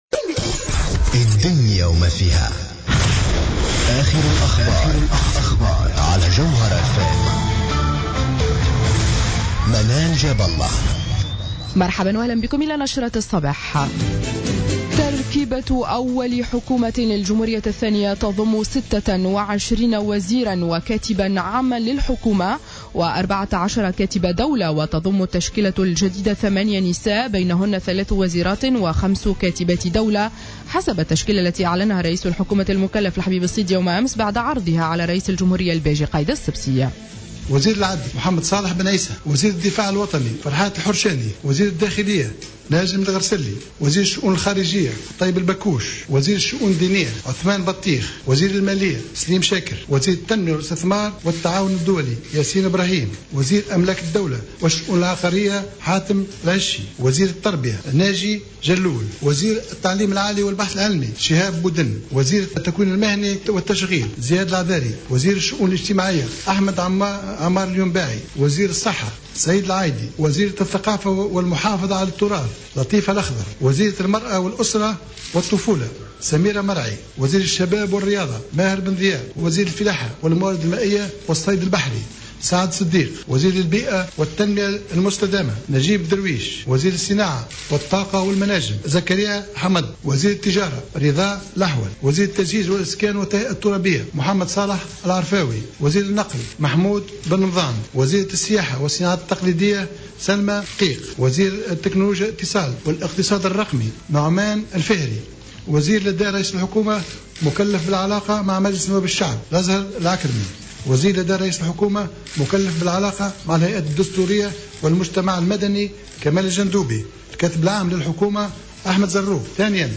نشرة أخبار السابعة صباحا ليوم الثلاثاء 03-02-15